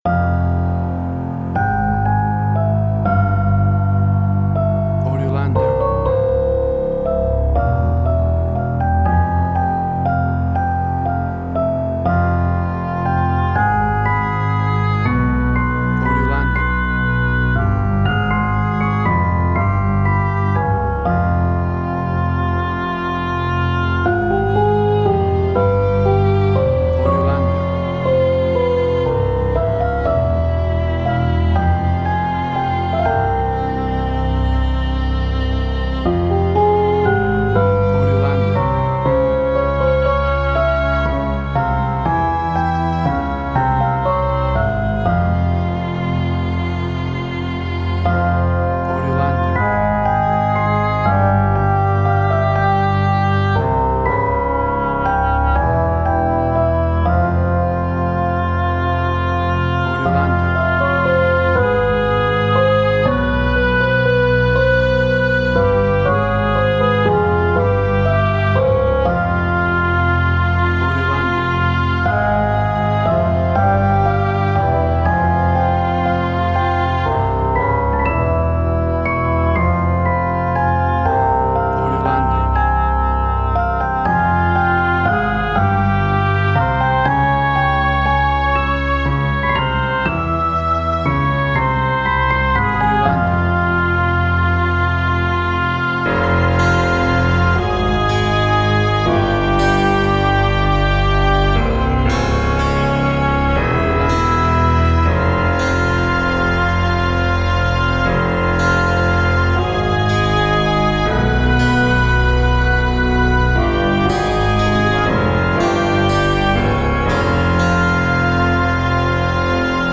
Indie Quirky.
Tempo (BPM): 120